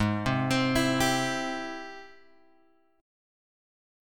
G# Augmented Major 7th